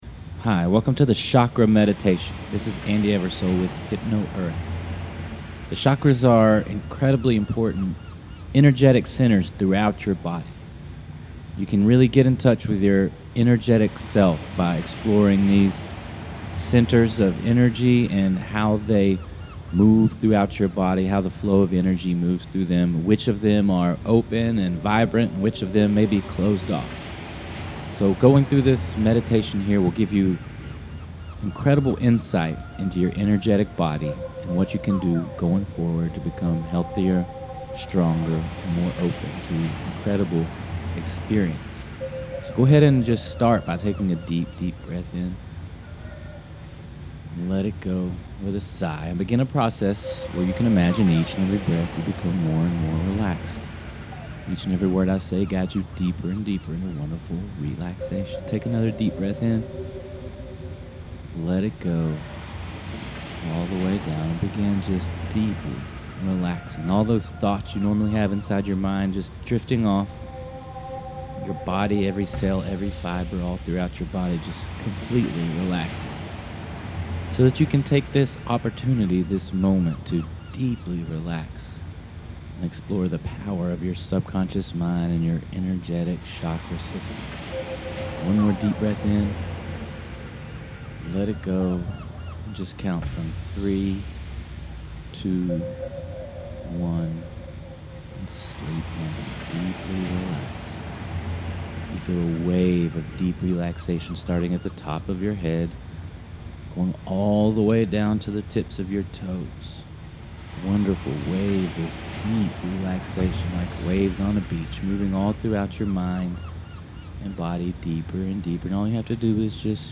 chakrameditation.mp3